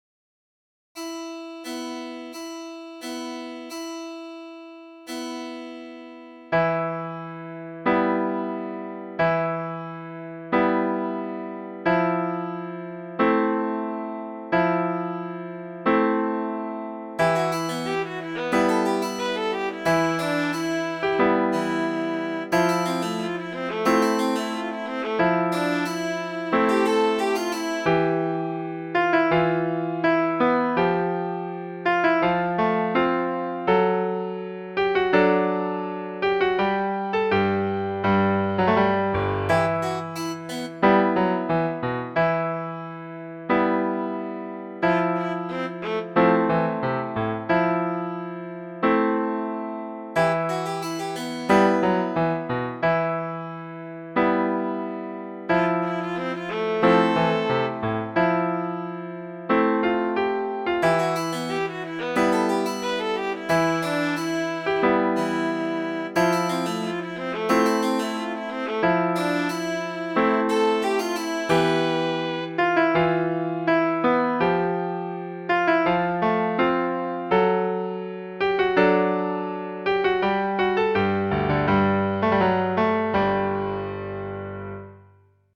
BALLADS